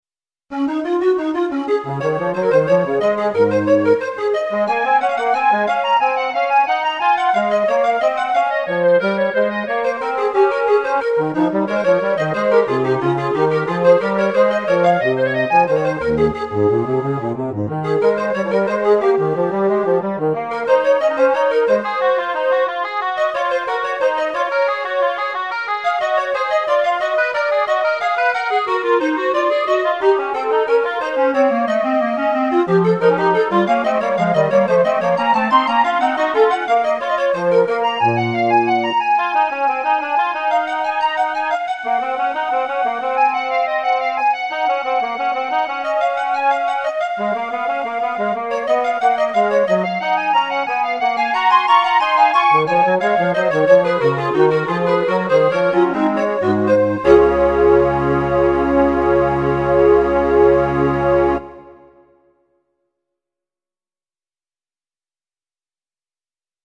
恐れ多くも半音上げました。